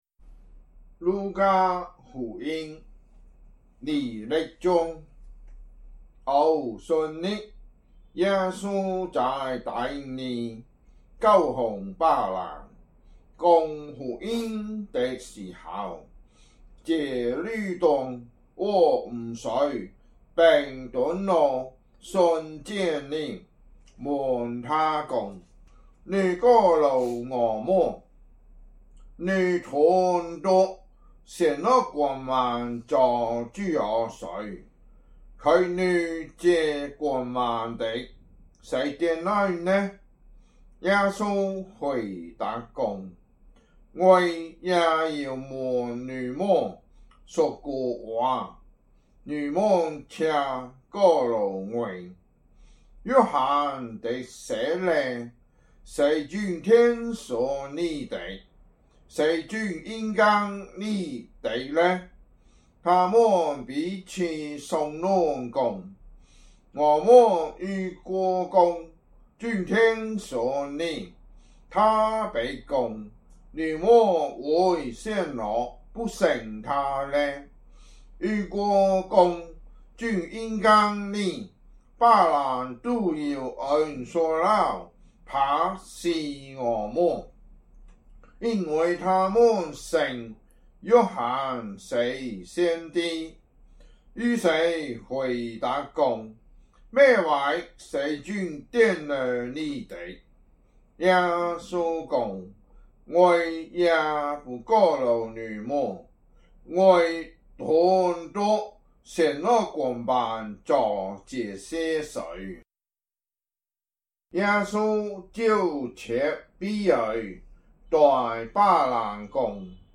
福州話有聲聖經 路加福音 20章